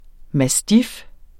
Udtale [ maˈstif ]